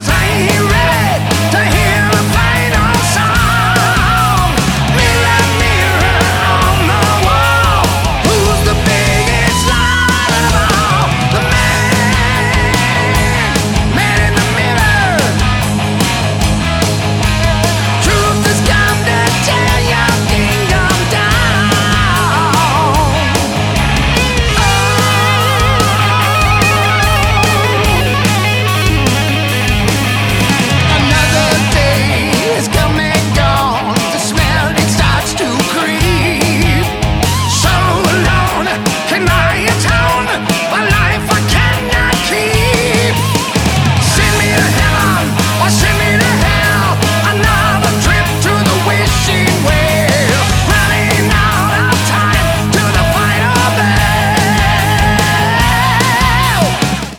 Lead Vocals
Lead Guitar
Bass Guitar
Drums